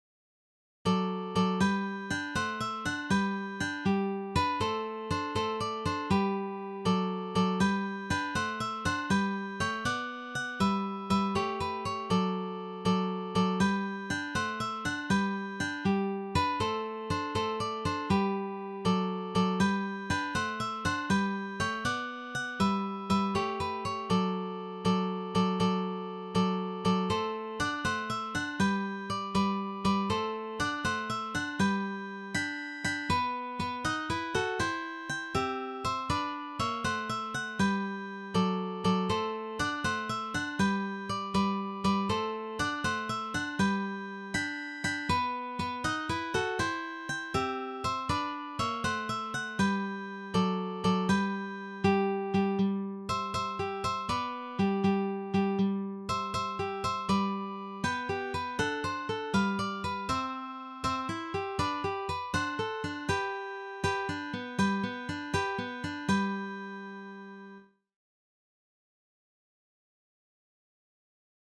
This Classical selection is arranged for guitar trio.